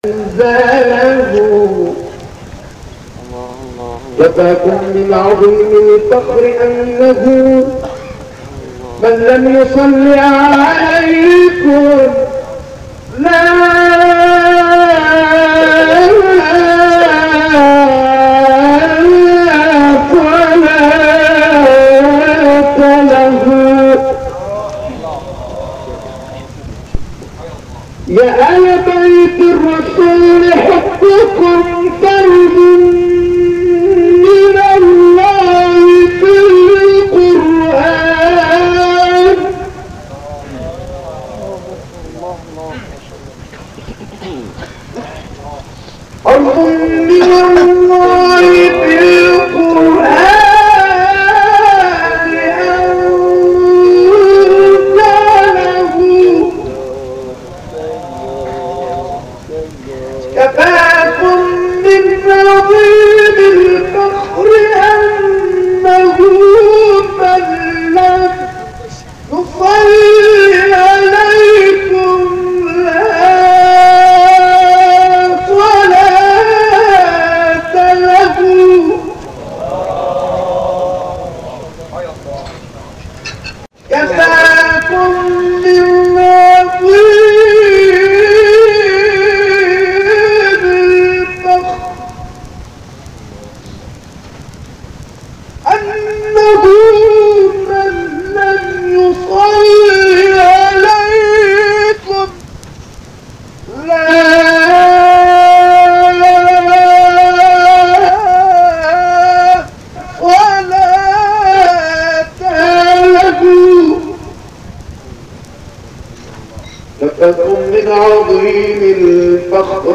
از طرفی ایشان بسیار شوخ و خوش‌صدا هم بود و جنس صدایشان همان جنس صدای مداح‌های آذری‌زبان بود.
صدای گرمی هم داشت و همین موجب شده بود تا در زمینه ابتهال بتواند فعالیت بیشتری داشته باشد.